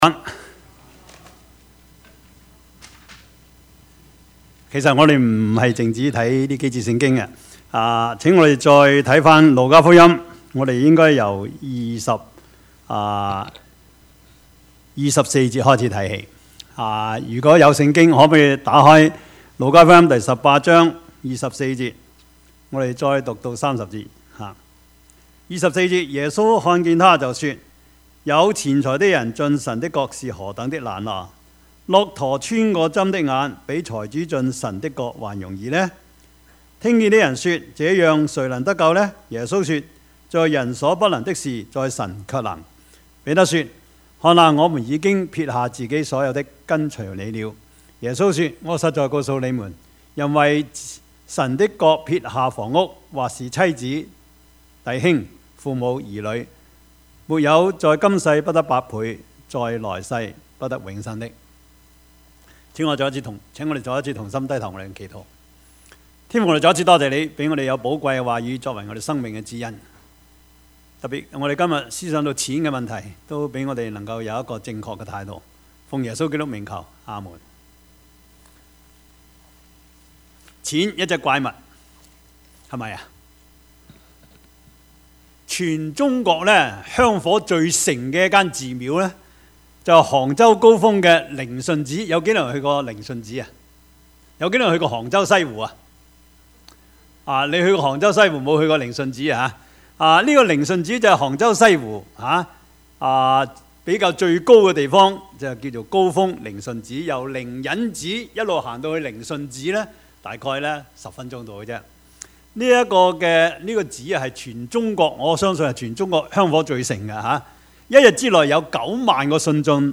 Service Type: 主日崇拜
Topics: 主日證道 « 苦難與榮耀 我們都是這些事的見證 »